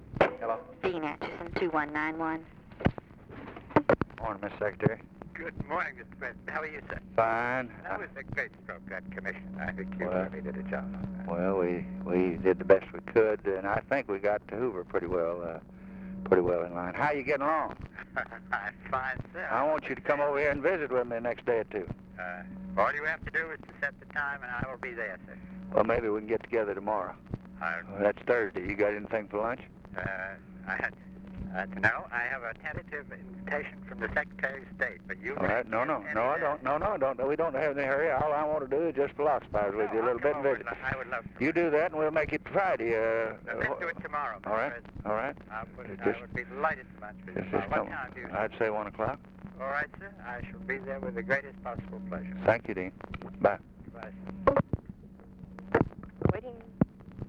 Conversation with DEAN ACHESON, December 4, 1963
Secret White House Tapes